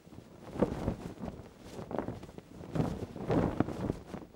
cloth_sail6.L.wav